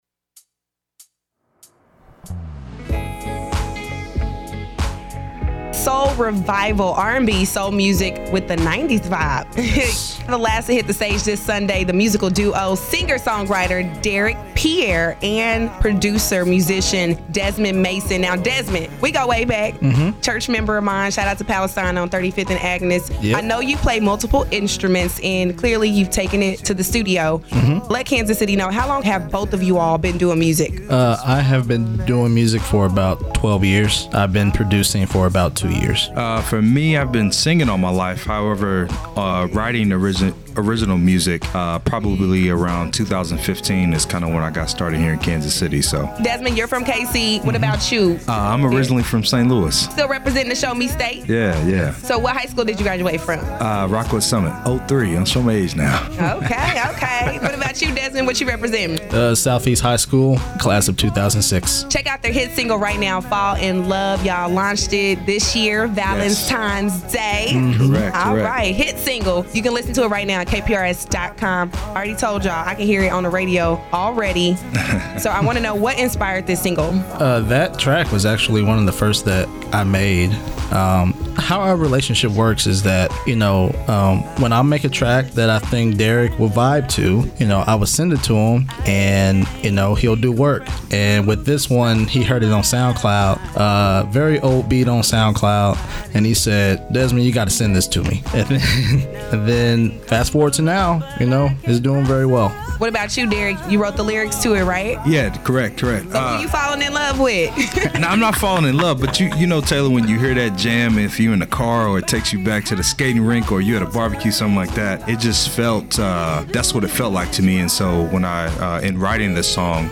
2017 KC’S NEXT MUSIC SHOWCASE FINALIST INTERVIEW